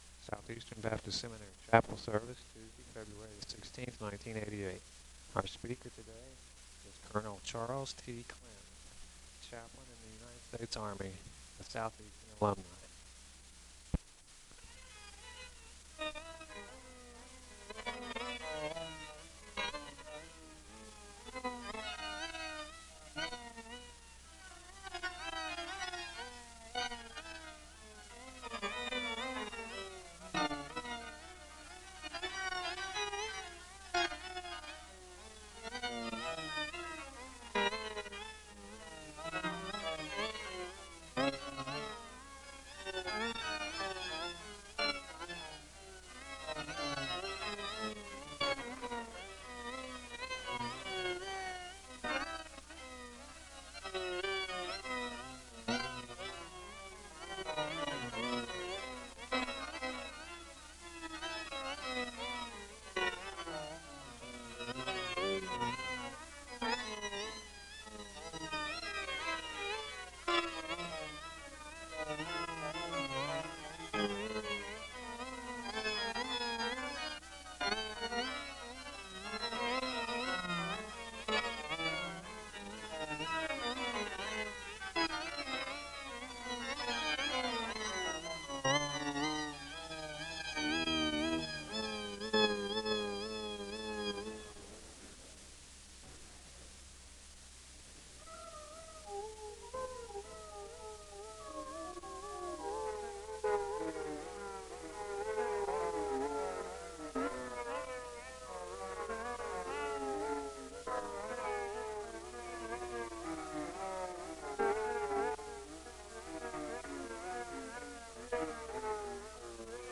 The service begins with organ music (0:00-4:57).
There is a welcome extended to the guests (4:58-6:38). There is a moment of prayer (6:39-8:42).
The service concludes with a prayer of benediction (24:30-25:18).